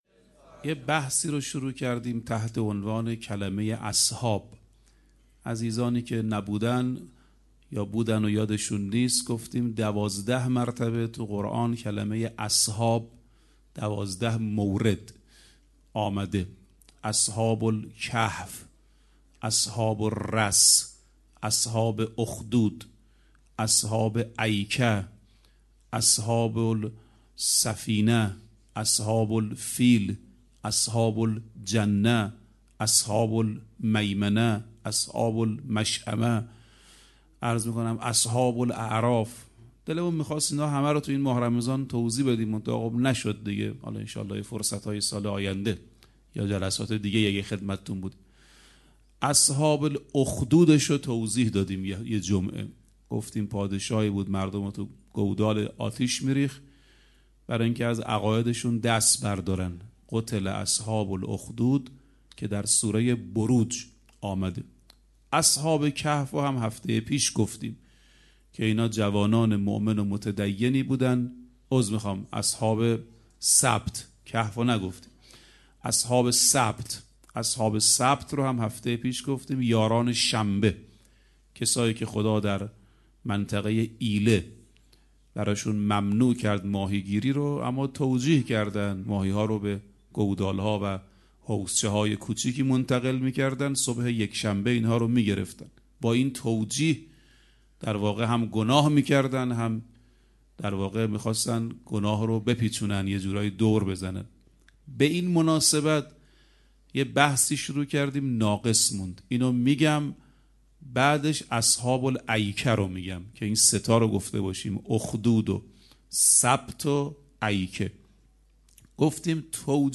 18 خرداد 97 - هیئت آل یاسین - سخنرانی